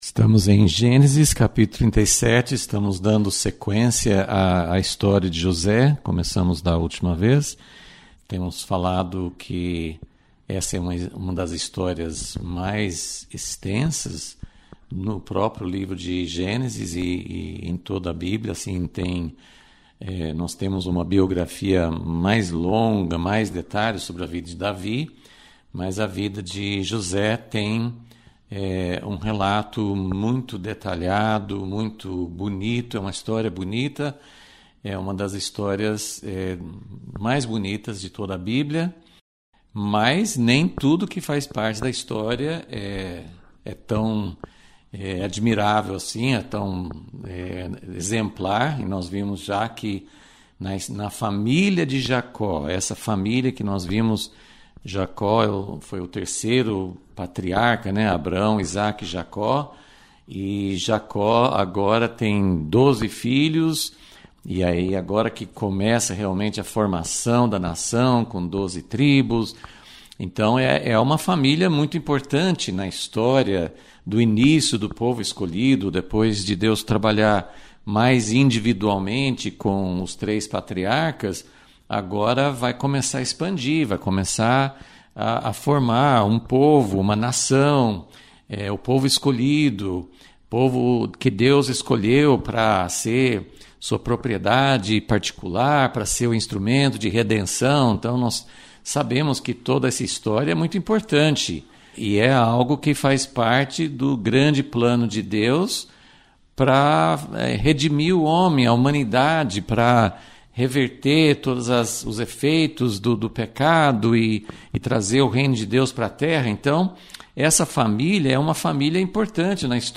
Aula 103 - Gênesis - Dá para perceber a providência divina durante o processo?